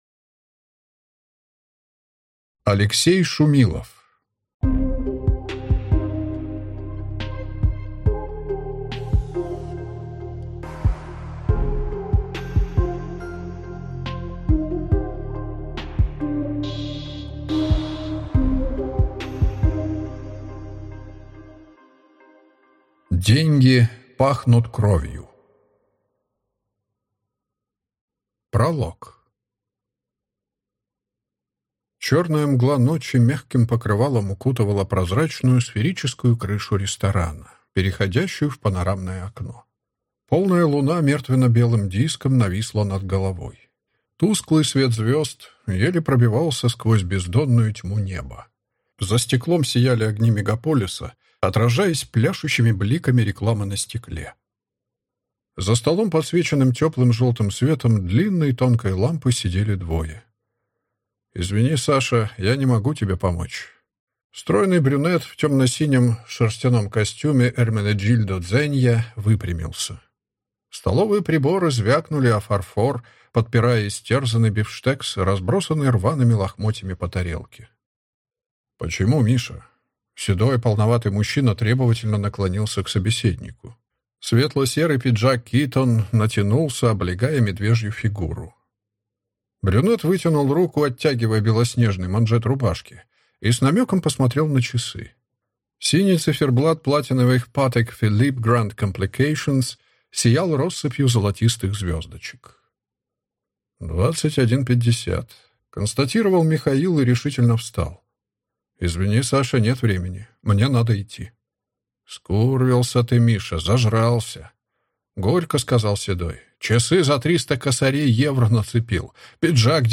Аудиокнига Деньги пахнут кровью | Библиотека аудиокниг